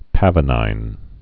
(păvə-nīn)